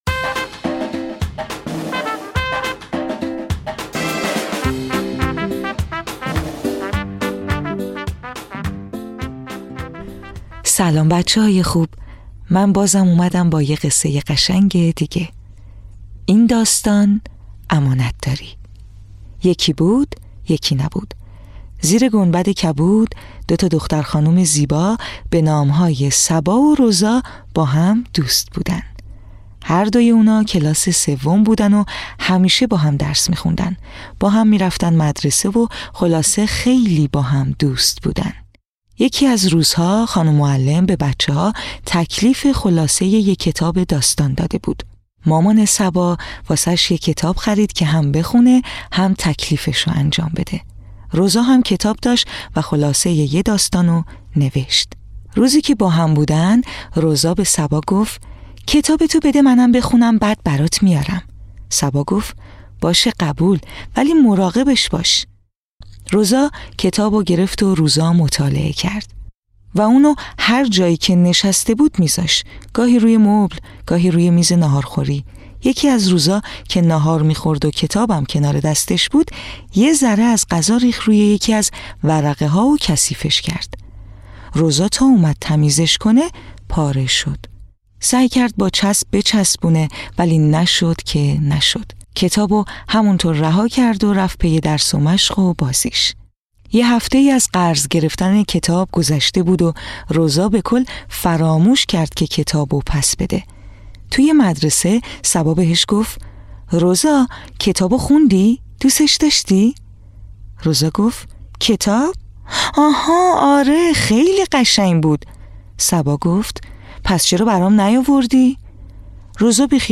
قصه‌های کودکانه صوتی - این داستان: امانت داری
تهیه شده در استودیو نت به نت